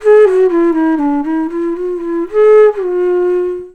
FLUTE-B07 -L.wav